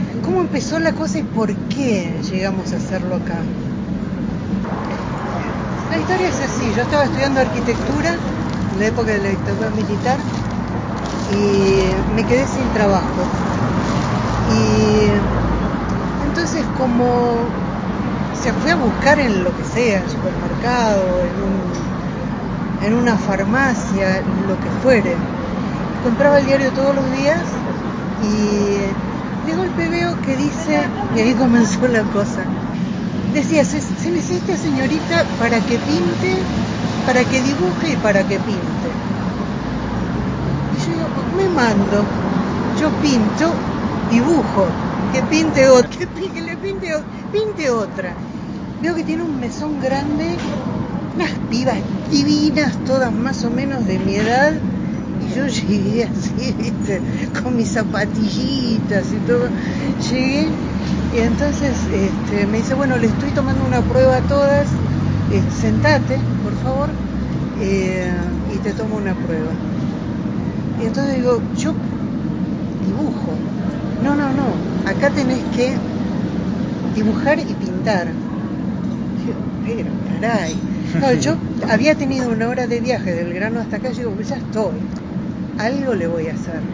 La calidad del audio no es la mejor, desde ya.